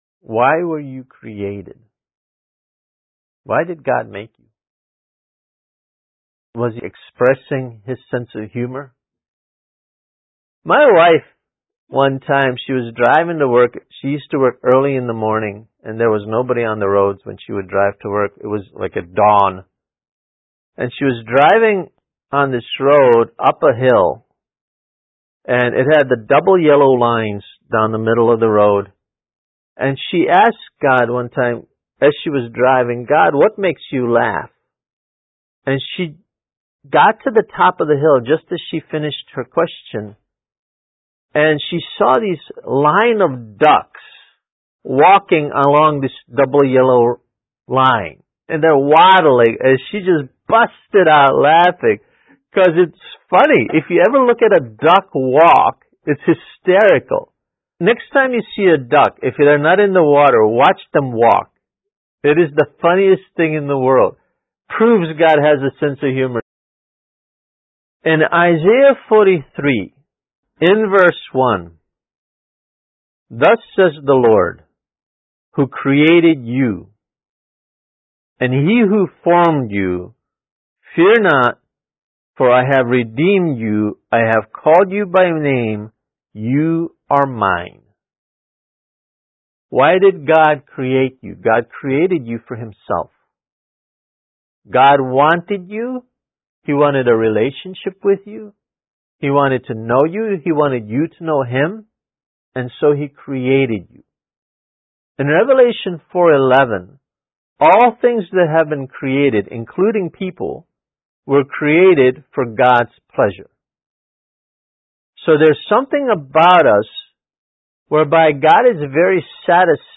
Kids Message: Why We Were Created